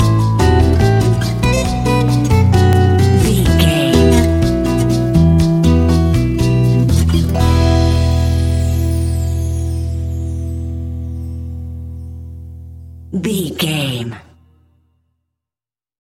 Aeolian/Minor
maracas
percussion spanish guitar
latin guitar